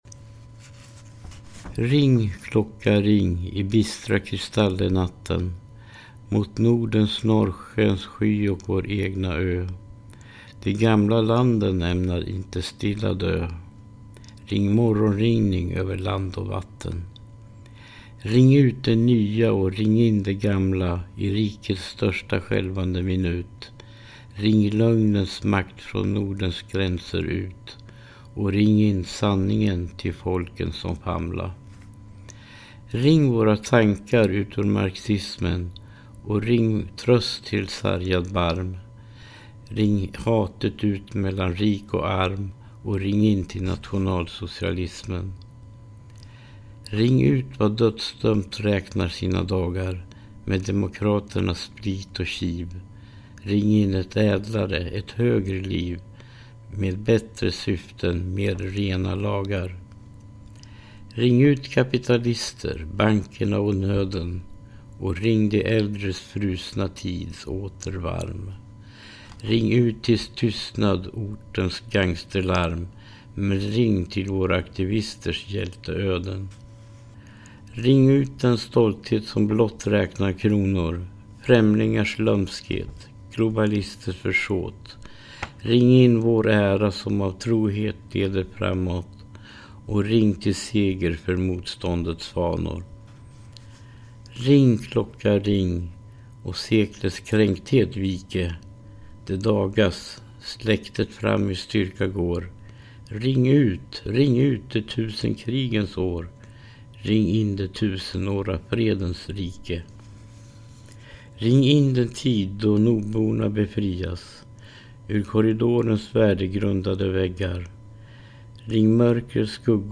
läser upp en egen tolkning av en känd dikt.